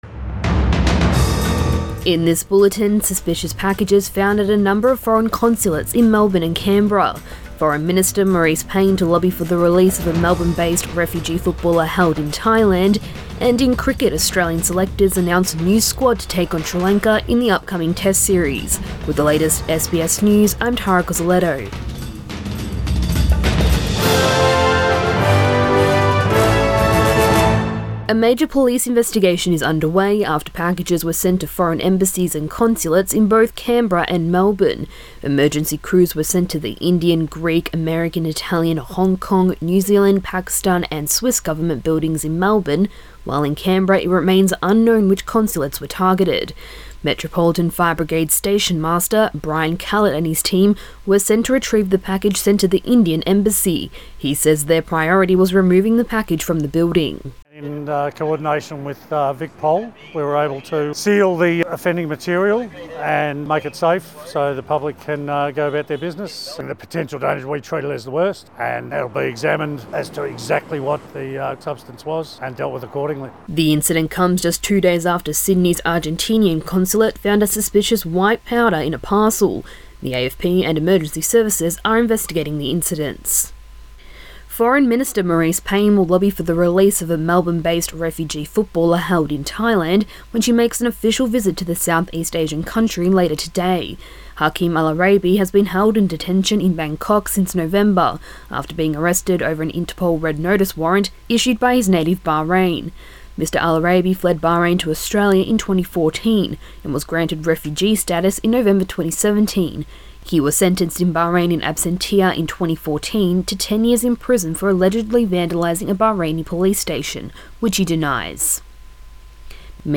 AM bulletin 10 January